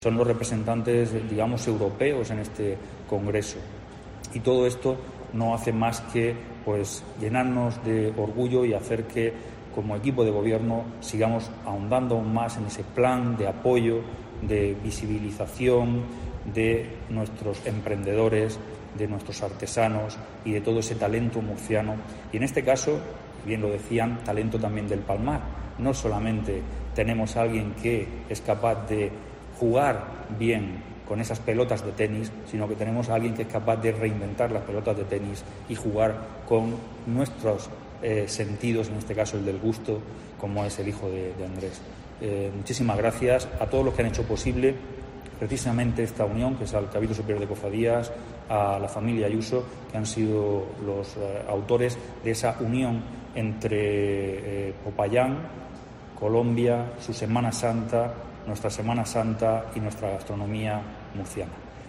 Jesús Pacheco, concejal de Turismo, Comercio y Consumo